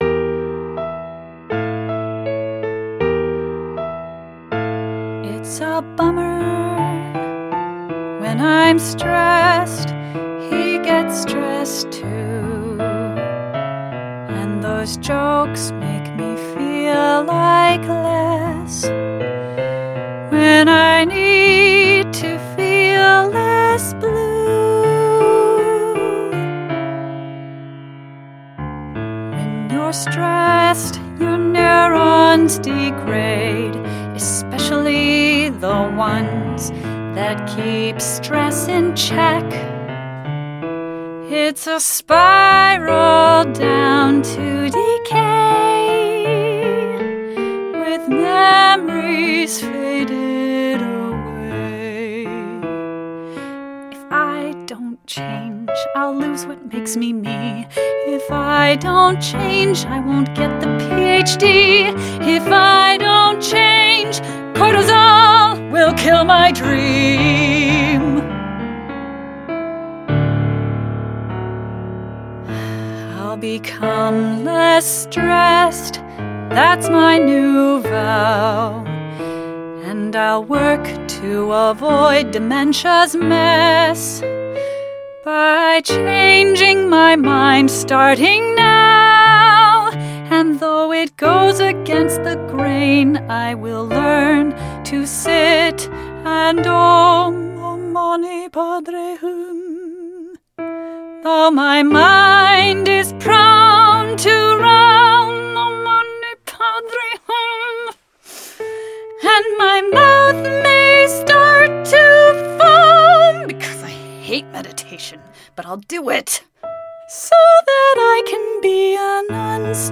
The songs are recorded with wonderful singing actors, but generally canned piano exported from my notation software.